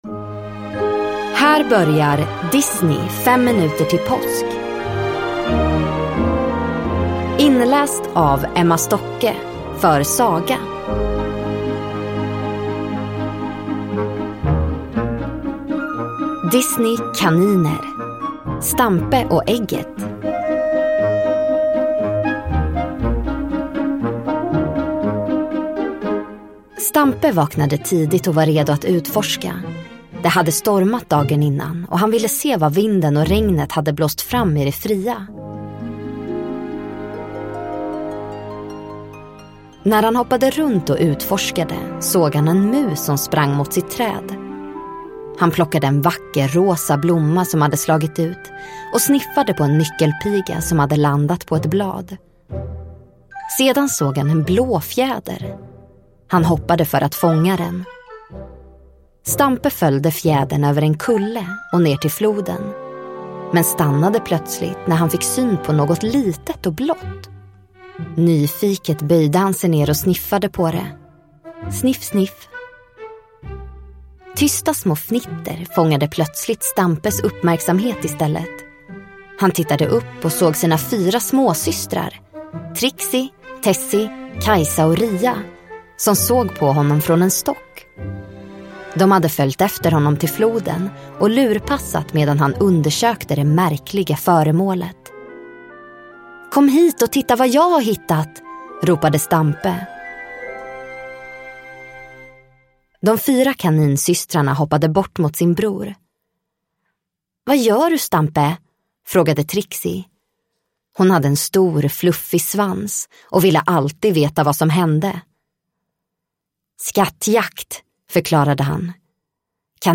Ljudbok
Nu med fantastisk musik och ljudeffekter!